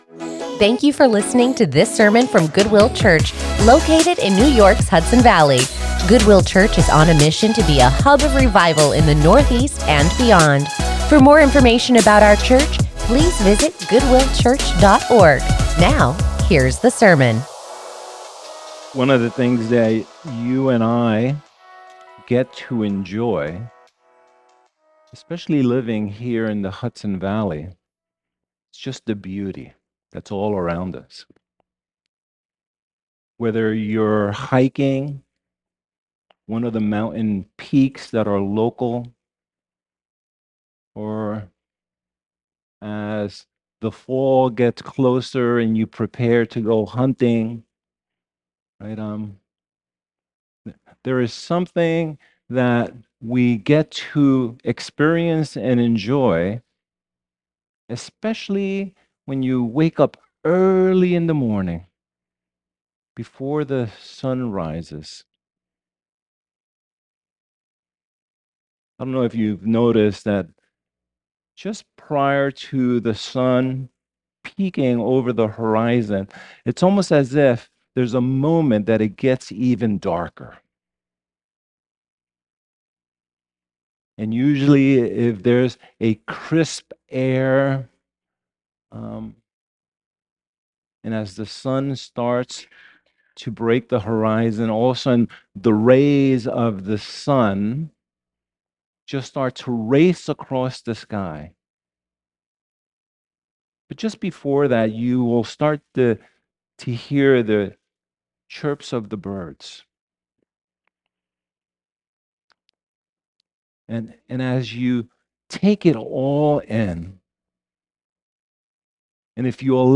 Join us in the study of God's Word as we continue our sermon series